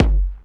59 BD 2   -R.wav